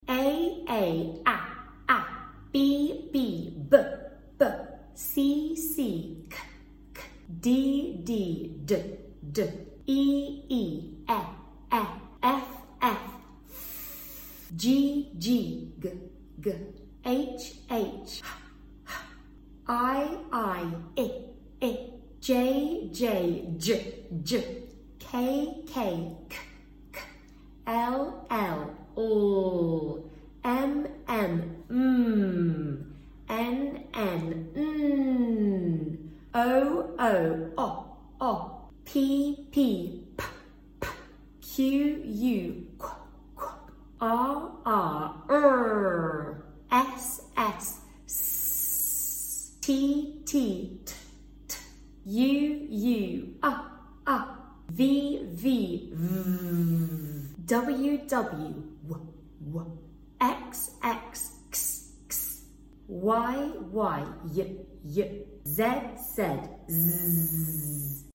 Here are all the English phonetic sounds with actions! When taught the sound alongside actions and images, children are able to make connections that help them recall the sound quicker than they would without.